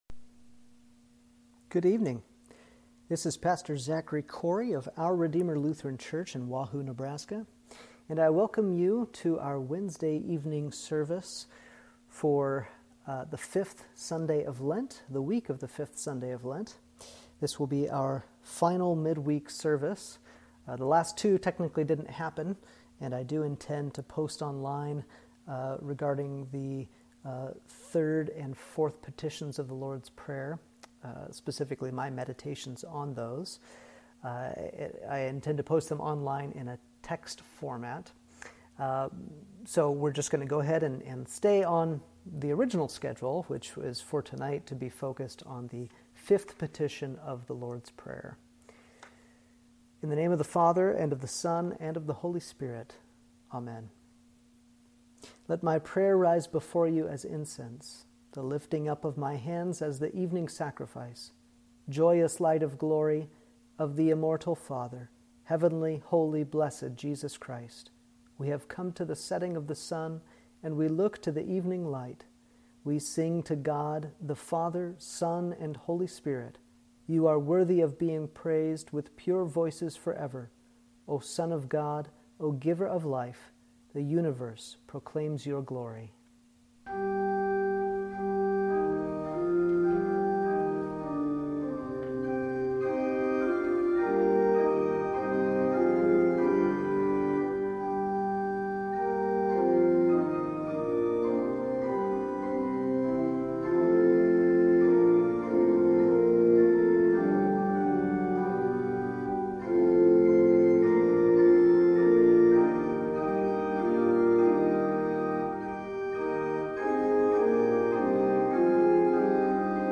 Service: Lenten Midweek (Lent 5 – Judica)